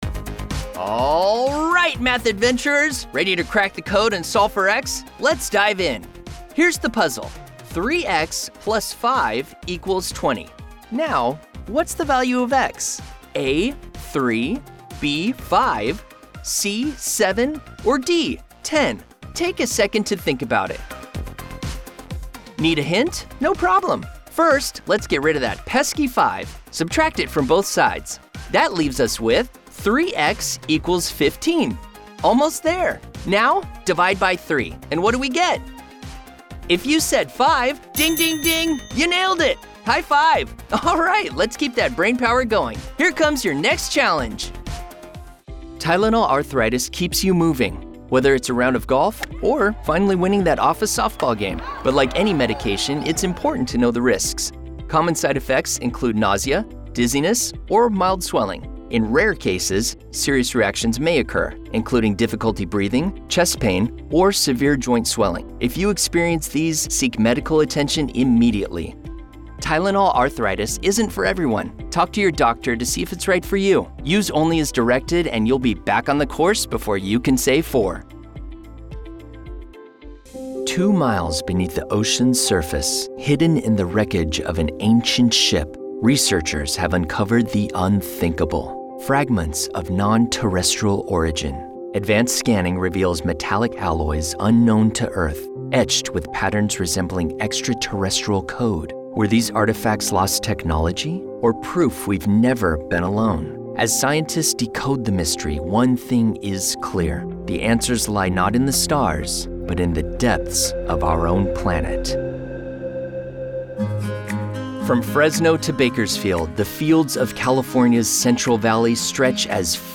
Narration
Young Adult
Middle Aged